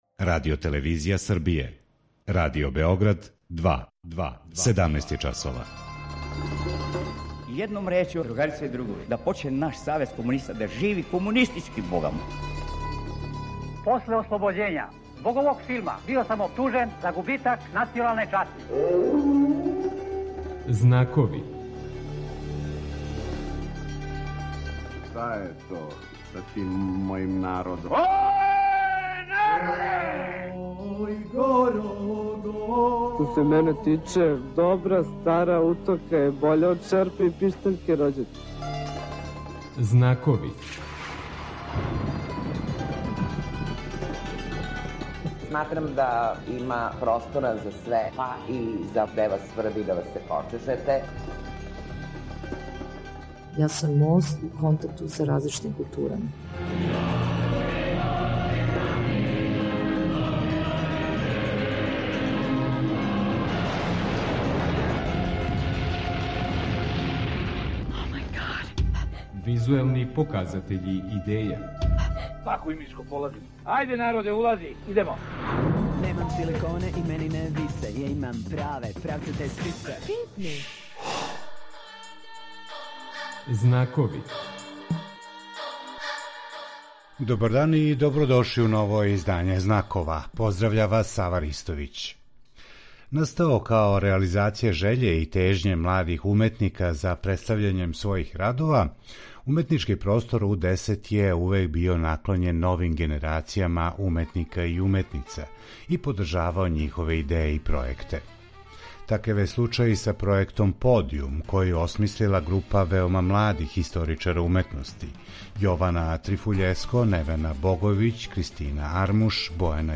Гошће